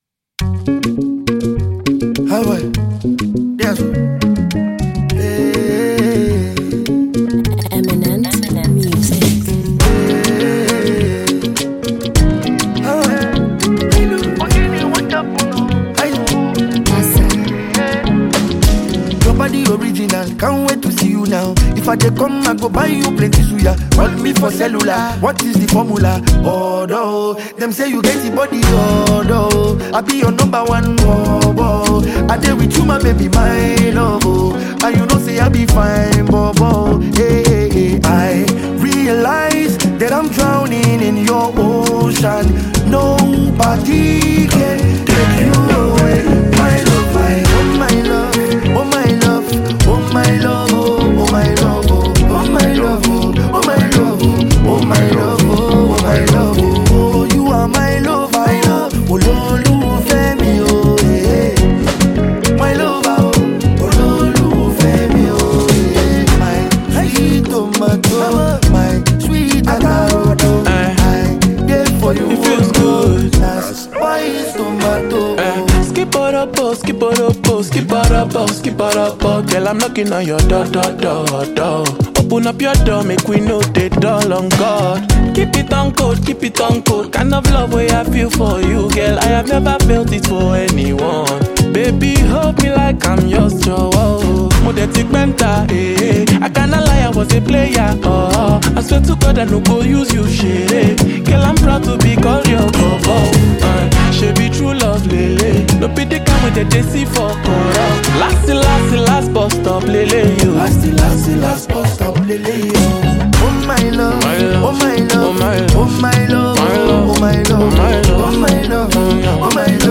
a Nigerian singer-songwriter and performer.